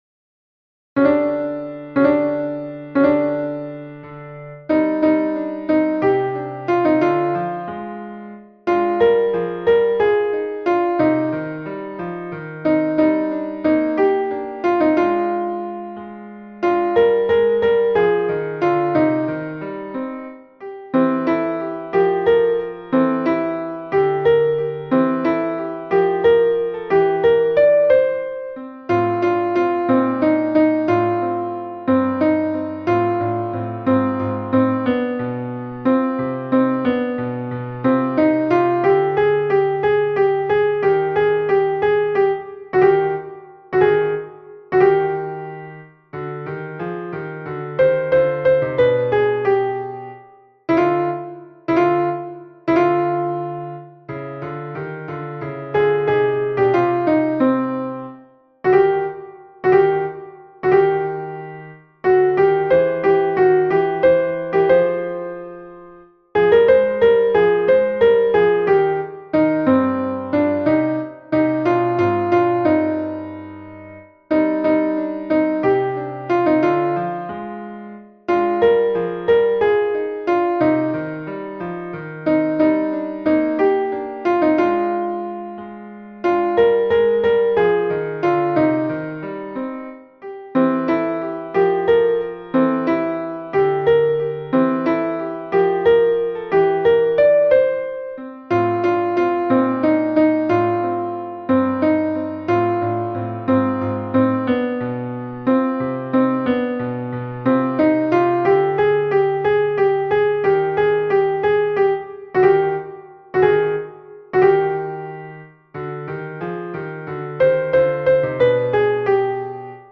MP3 version piano
Padam padam soprano (piano)
padam-padam-soprano.mp3